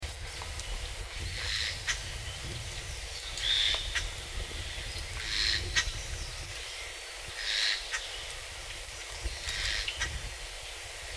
Black Headed Jay
Garrulus lanceolatus